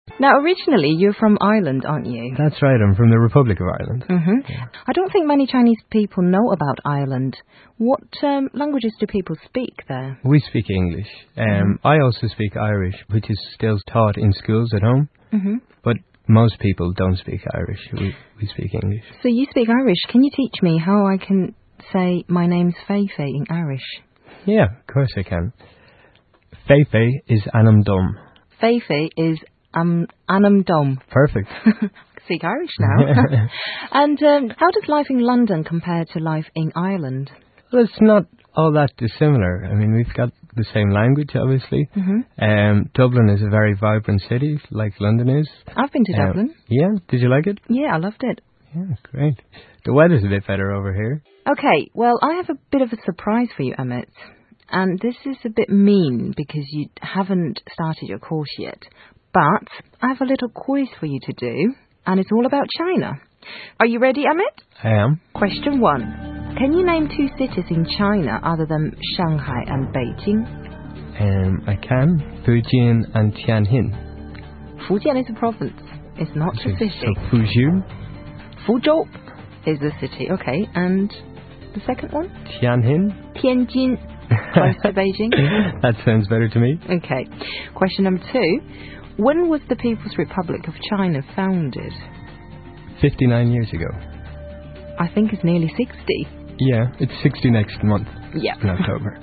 访谈（2）